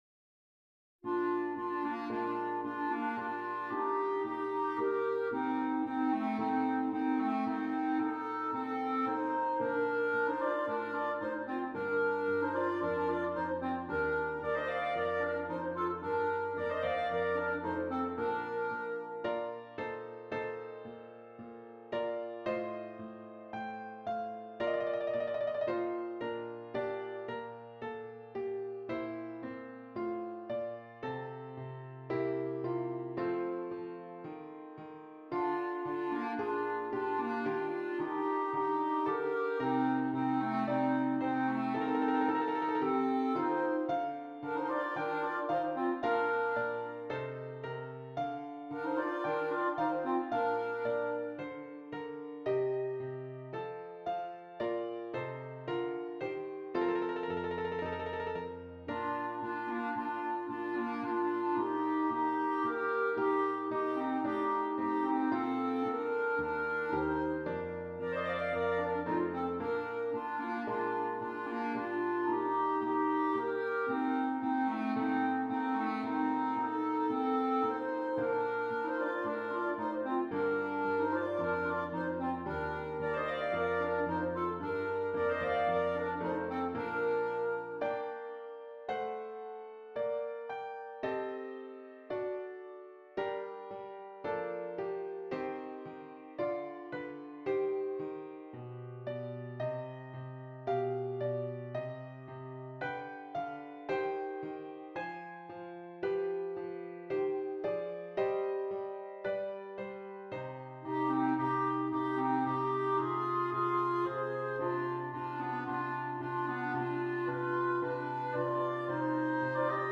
2 Clarinets and keyboard